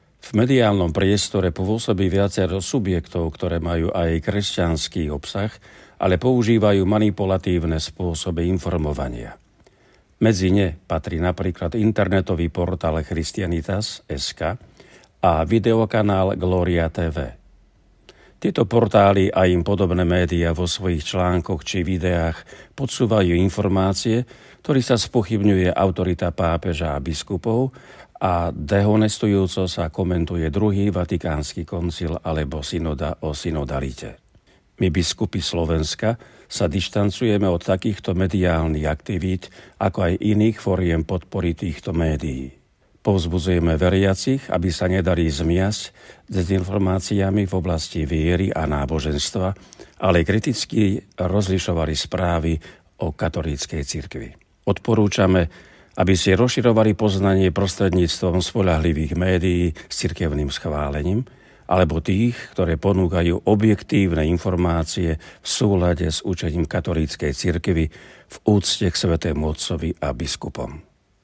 AUDIO (hovorí košický arcibiskup metropolita a predseda KBS Mons. Bernard Bober)